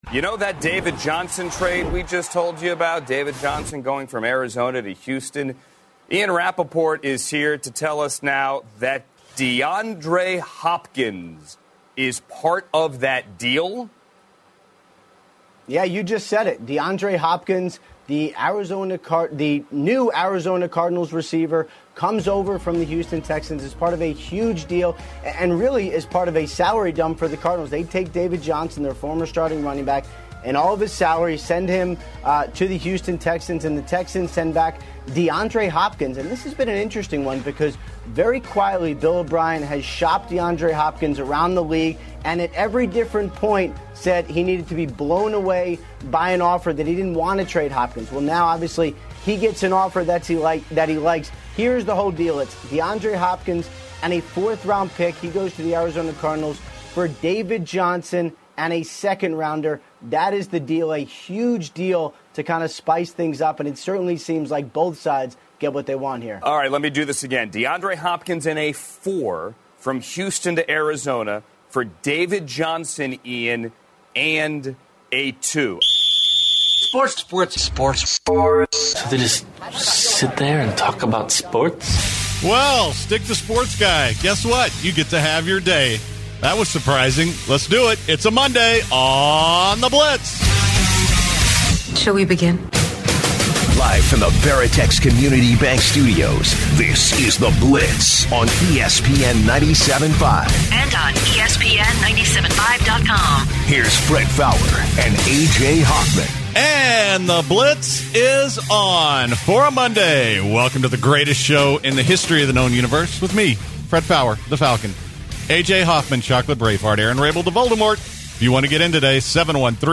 Callers call in to vent their frustrations about Bill O'Brien.